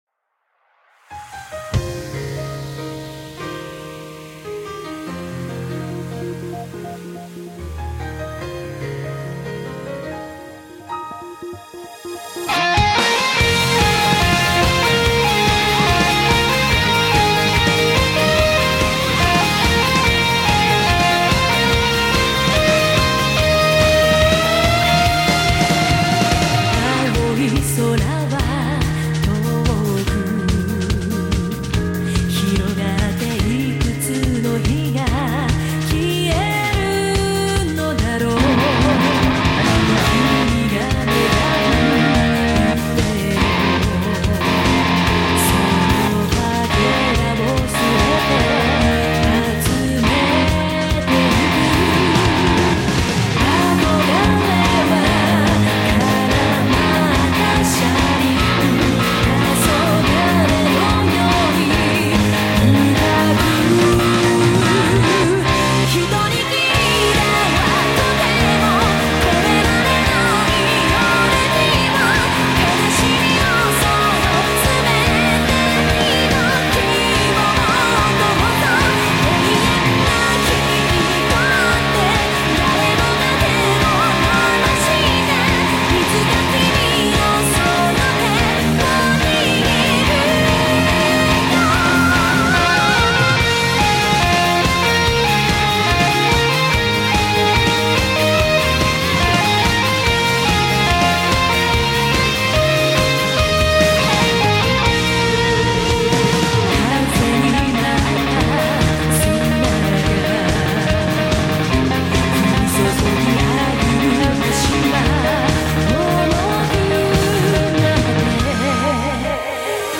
原曲非常燃
谱内音轨：电吉他轨